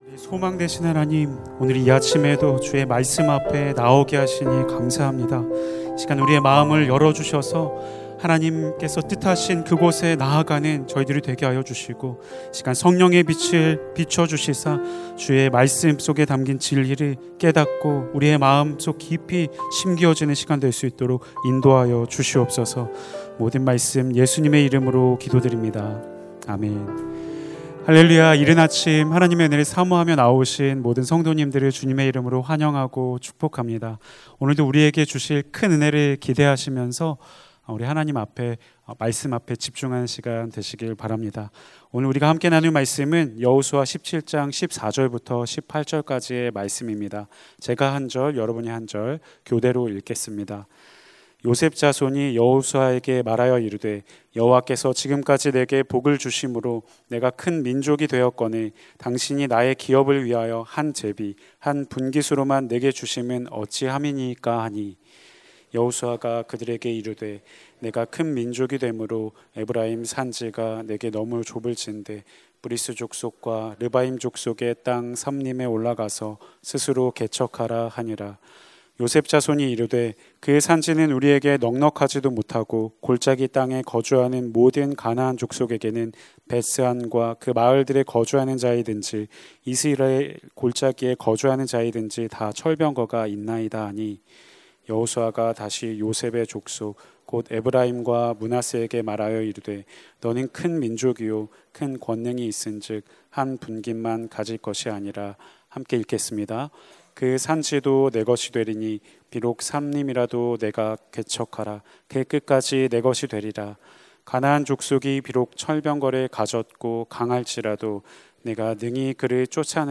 2025-10-01 새벽기도회
[새벽예배]